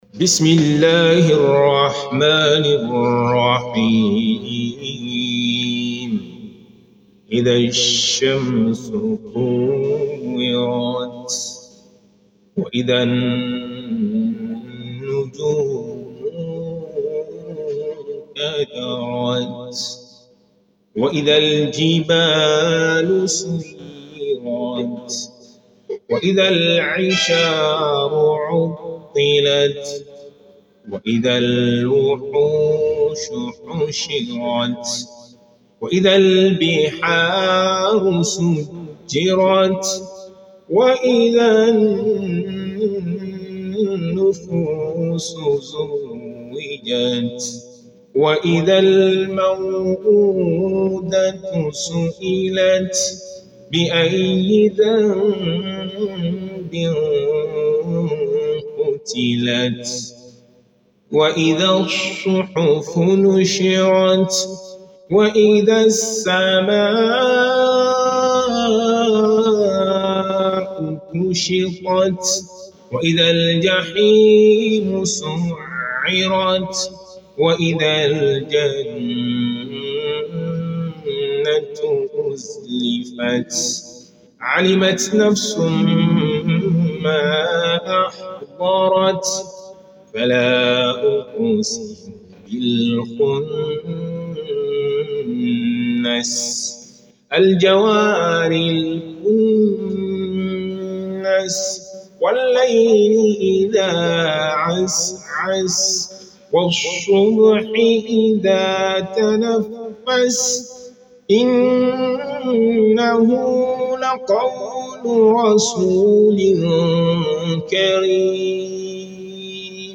قراءة